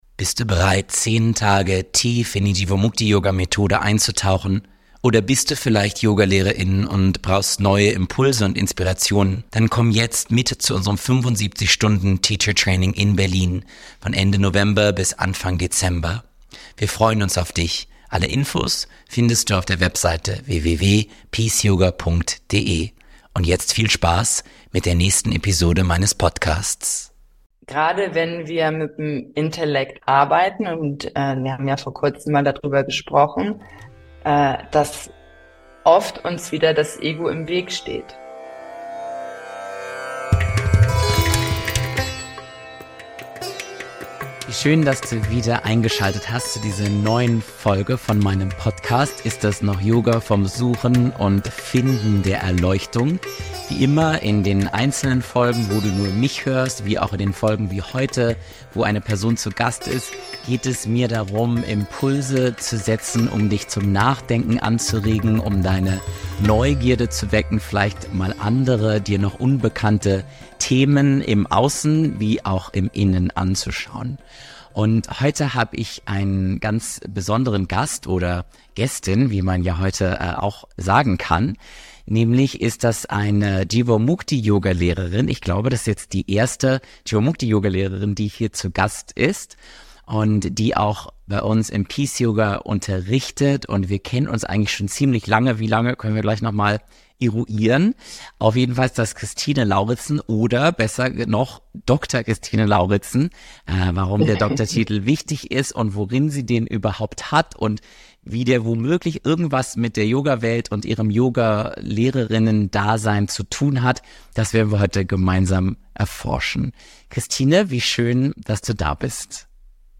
Wie treffen wir Entscheidungen? - Gespräch